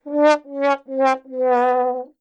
Play Dota Sad Trombone - SoundBoardGuy
Play, download and share Dota Sad Trombone original sound button!!!!
misc_soundboard_sad_bone.mp3